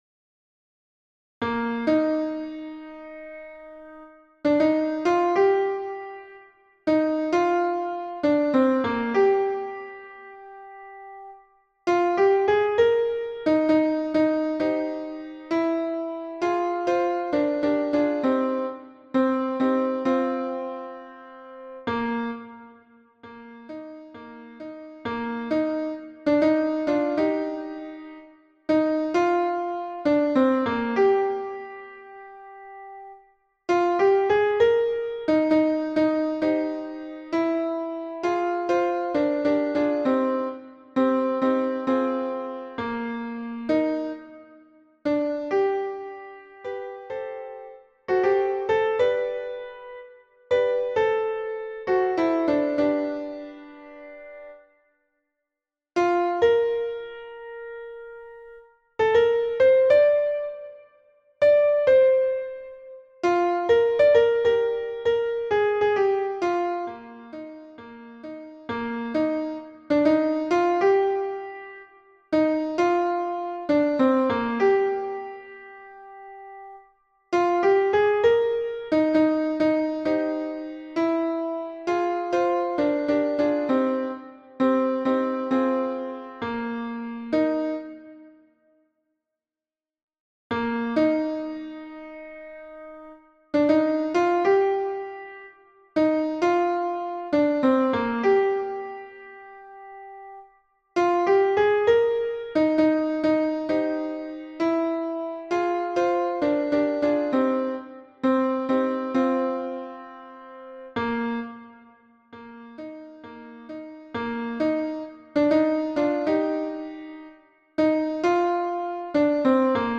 Alto et autres voix en arrière-plan